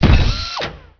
step.wav